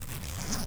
ZIPPER_Short_2_mono.wav